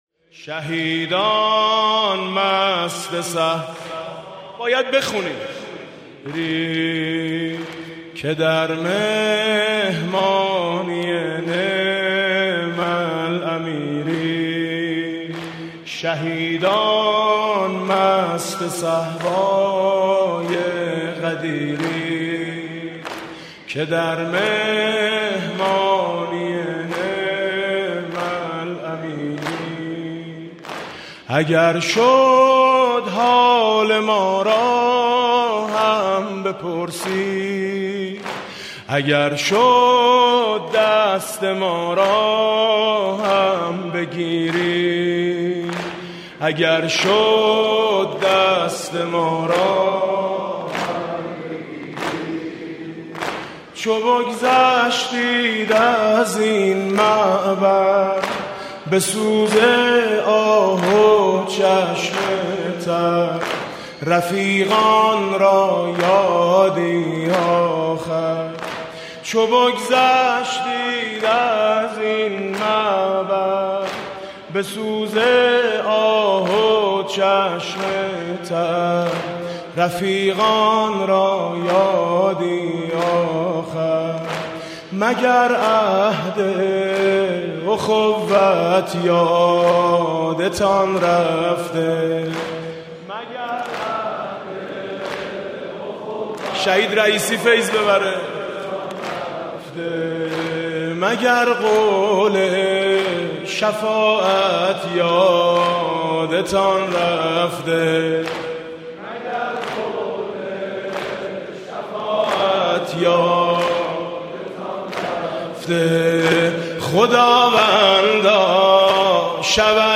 بیت رهبری [حسینیه امام خمینی (ره)]
مناسبت: شب هفتم محرم
با نوای: حاج میثم مطیعی
درد دلی با شهدا (نوحه)